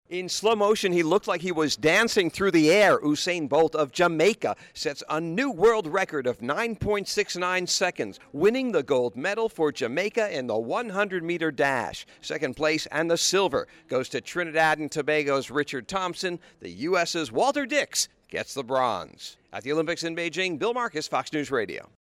2008 Beijing Olympics, China, Fox News Radio, Selected Reports: